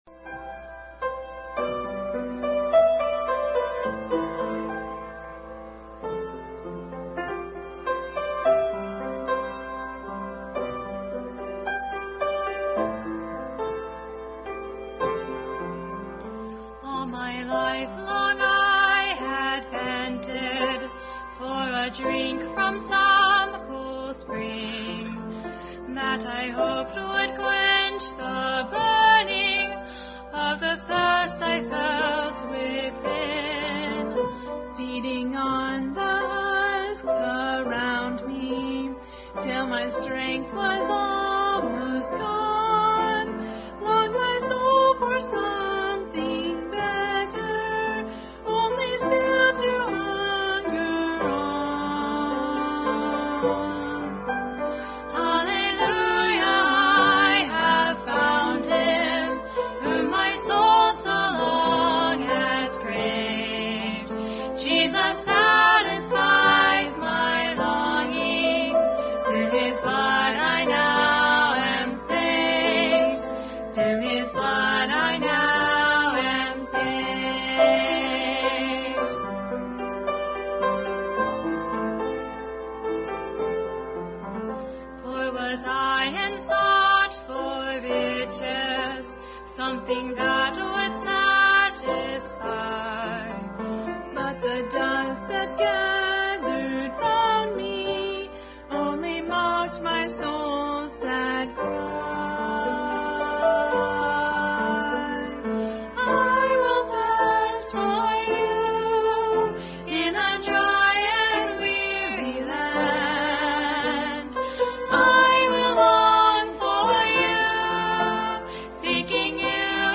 Bible Text: Philippians 3:10, Acts 16:25-34, Matthew 7:23, Exodus 5:1-2, John 17:3, Exodus 15:6, John 1:12, Luke 5:17, John 11:28 | Family Bible Hour.
“Satisfied”, Duet